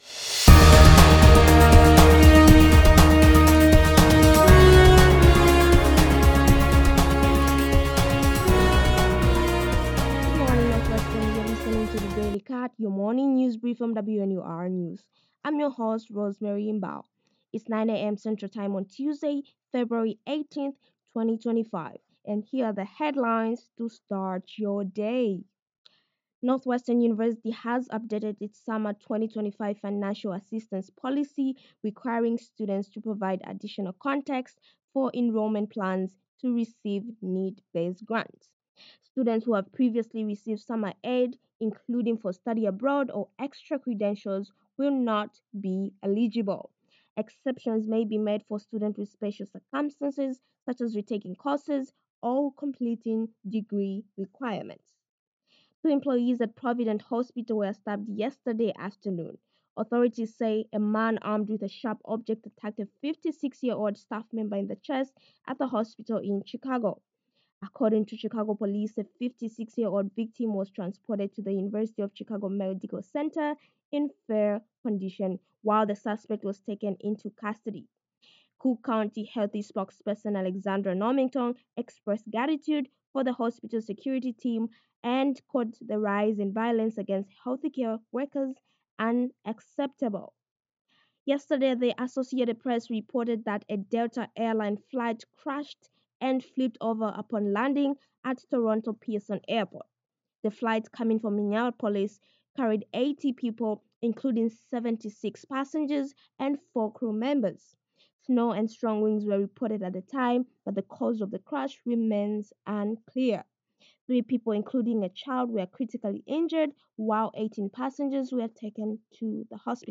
February 18, 2025: Northwestern summer aid, Provident hospital crime, Delta Airline crash,US-Russia talks. WNUR News broadcasts live at 6 pm CST on Mondays, Wednesdays, and Fridays on WNUR 89.3 FM.